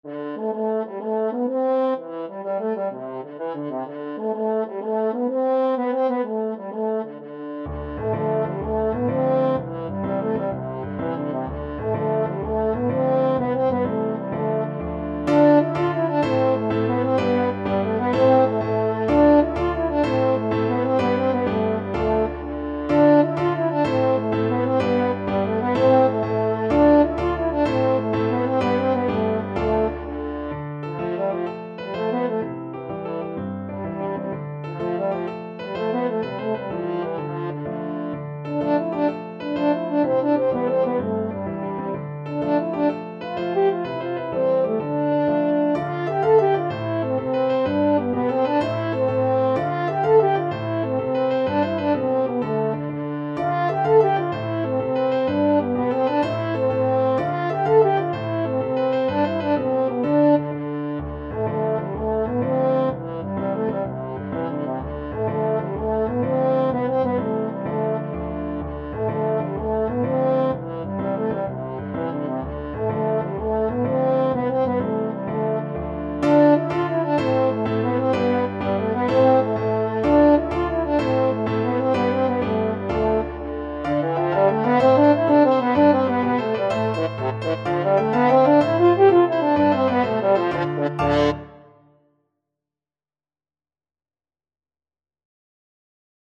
French Horn
Traditional Music of unknown author.
Fast .=c.126
12/8 (View more 12/8 Music)
Irish